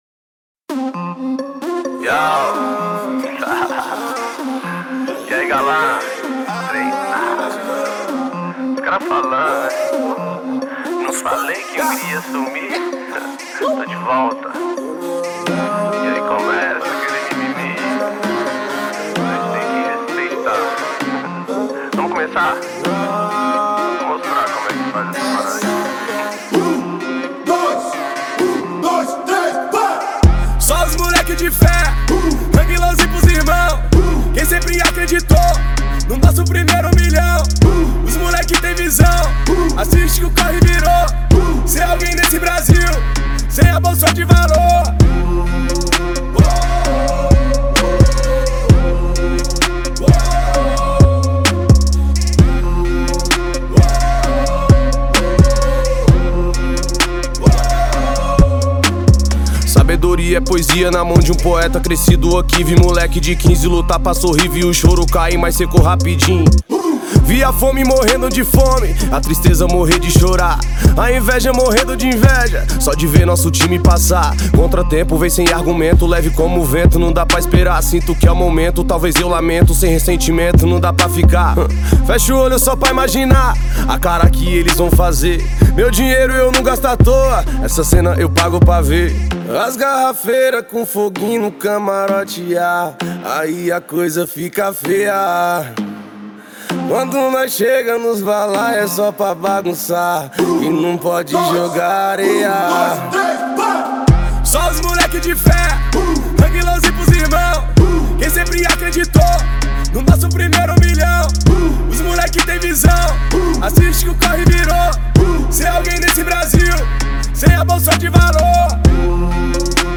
2025-02-24 21:56:24 Gênero: Hip Hop Views